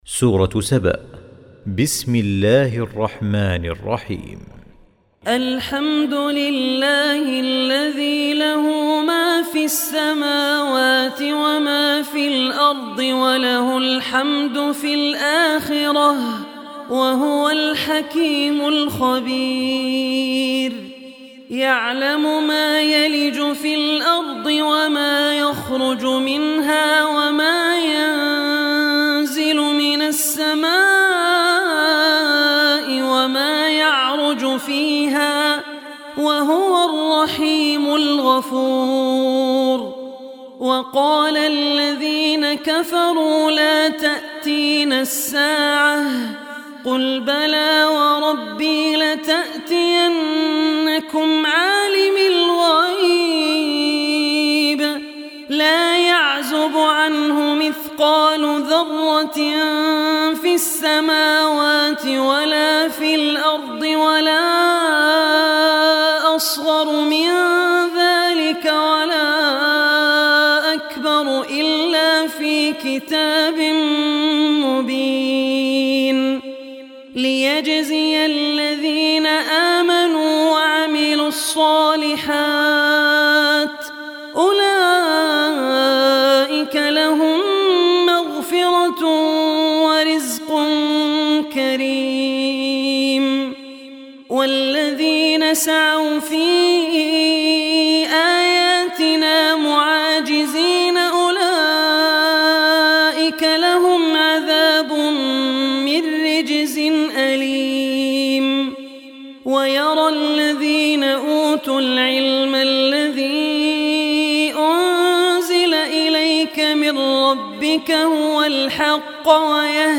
Surah Saba Recitation